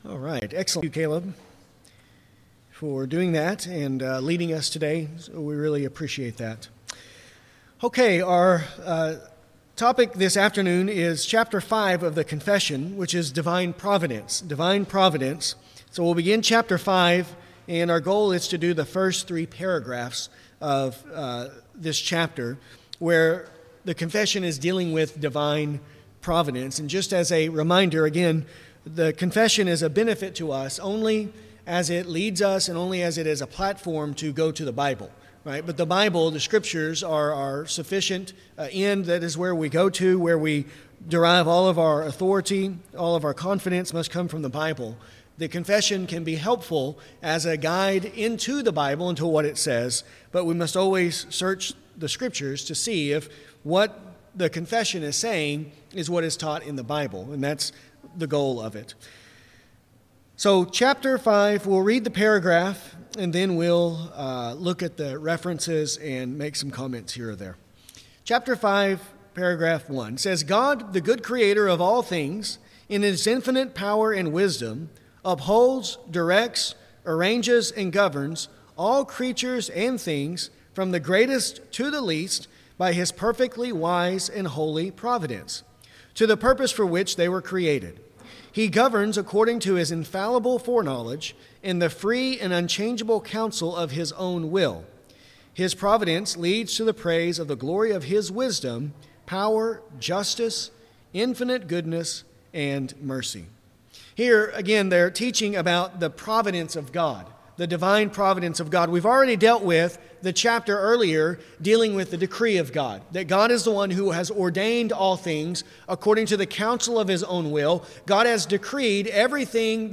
This lesson covers paragraphs 5.1 – 5.3 .